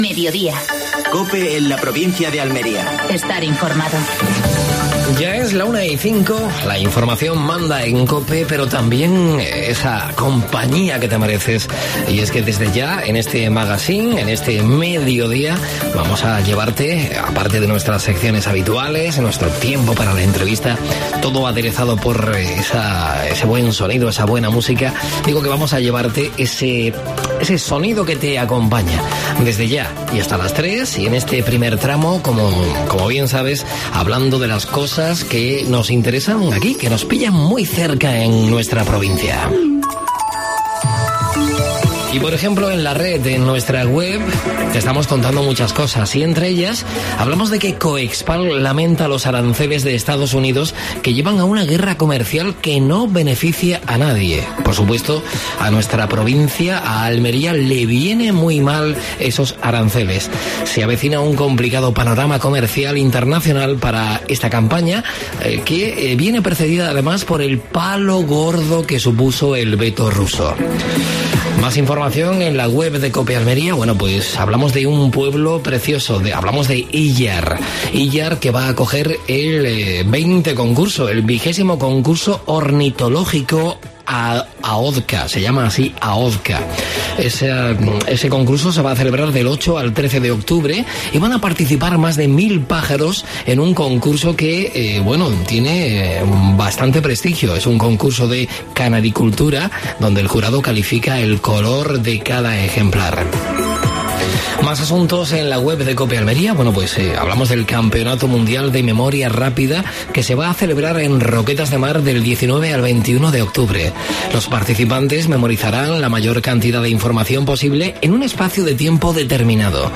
AUDIO: Actualidad en Almería. Entrevista a Francisco García (alcalde de Almócita).